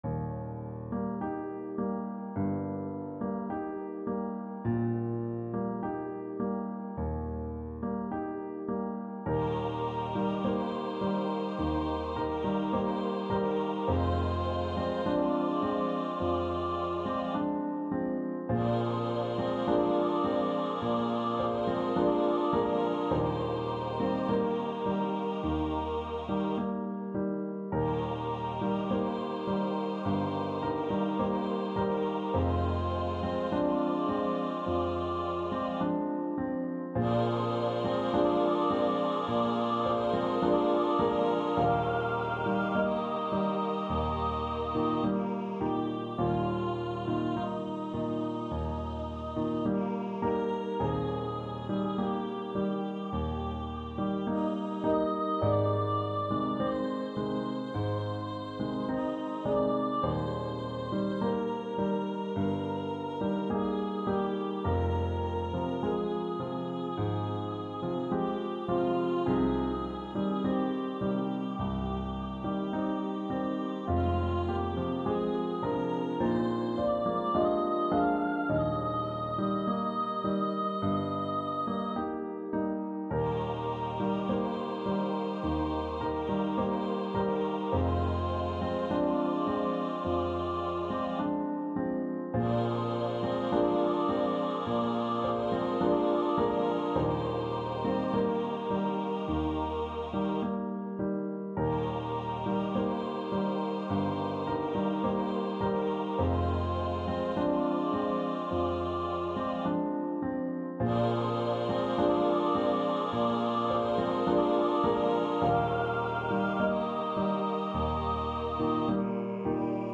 • Music Type: Choral
• Voicing: SATB, Soprano Solo, Tenor Solo
• Accompaniment: Piano
• Season: Christmas